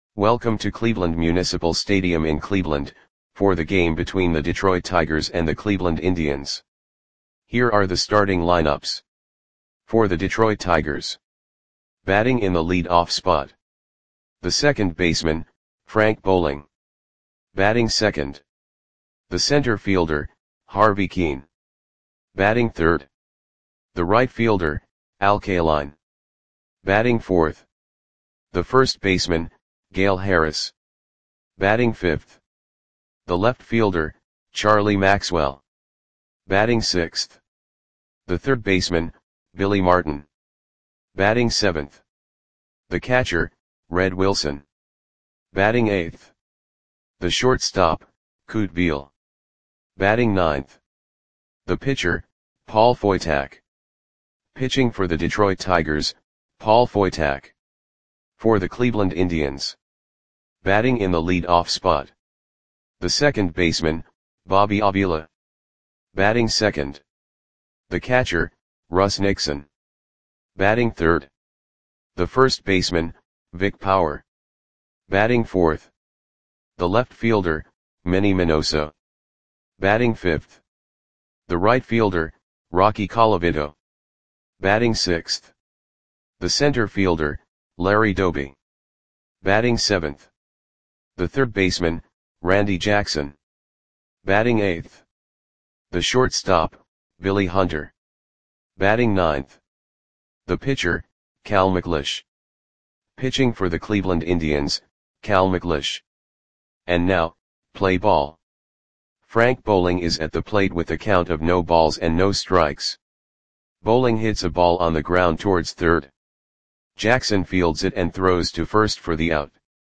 Audio Play-by-Play for Cleveland Indians on September 27, 1958
Click the button below to listen to the audio play-by-play.